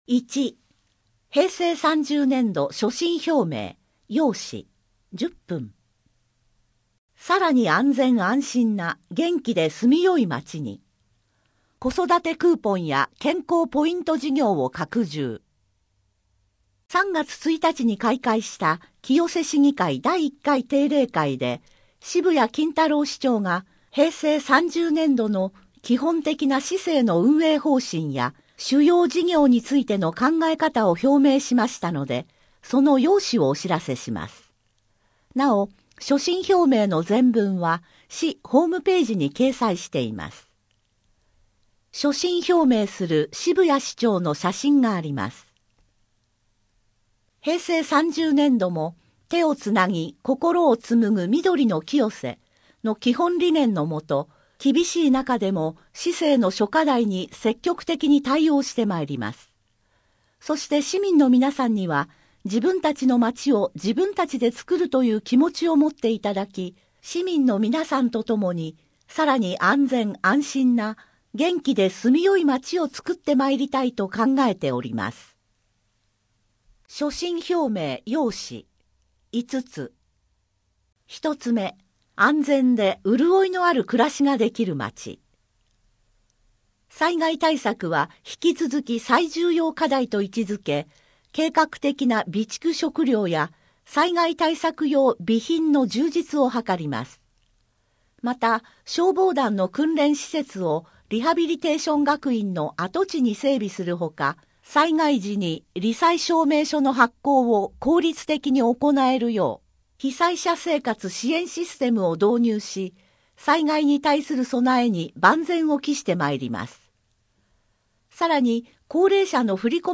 第3回農業委員会 原動機付自転車等の廃車・住所変更・名義変更 新米パパ・ママのための応急手当講習会 無料相談会 リサイクル 寄附寄贈 人口と世帯 健康づくり 4月の無料相談 声の広報 声の広報は清瀬市公共刊行物音訳機関が制作しています。